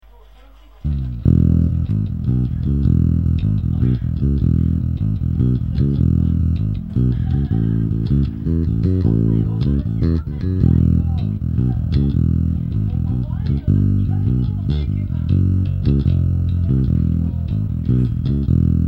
ErnieBall Musicman StingRay
Body Ash
Neck Selected Maiple
Pickup Musicman Hum backing with 3band EQ
買ったばかりなので何ともいえませんが、ボディの鳴りはそこそこいいです。